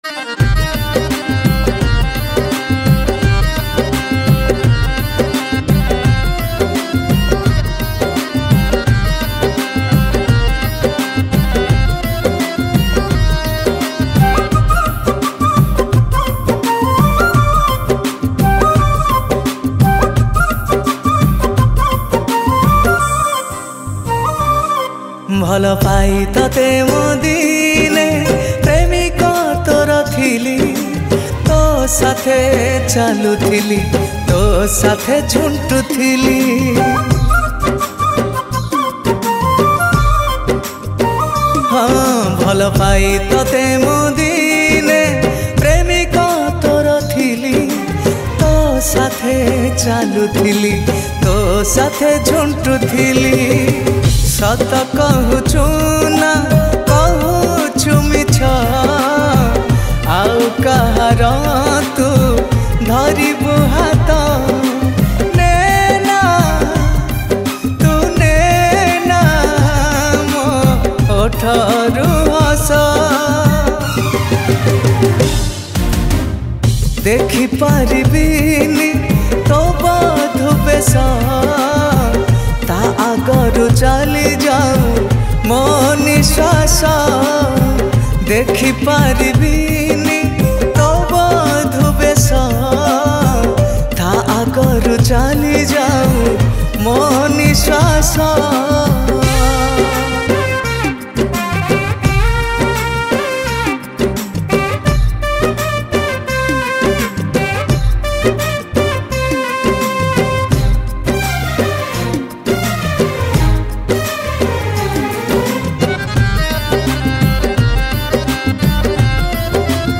• Song Type :Sad